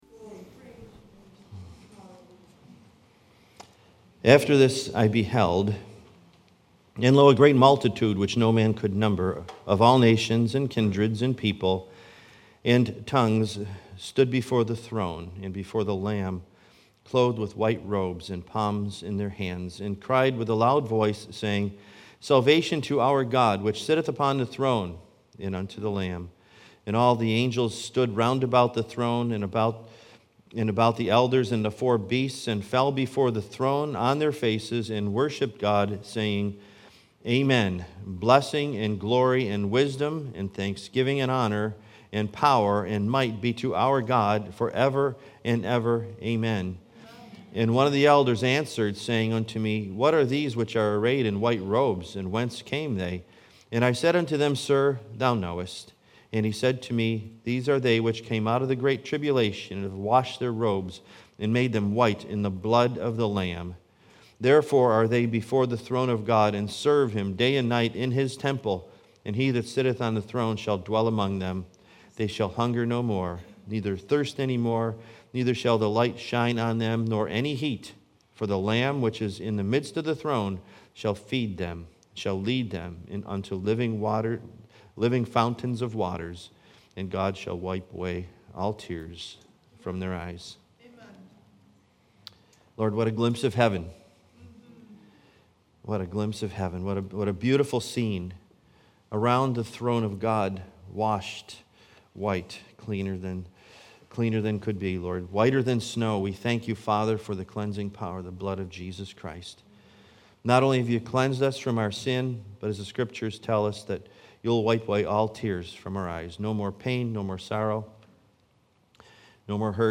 Acts 1:2-8: Please note, due to technical issues the last 5 minutes of this service was unable to be recorded.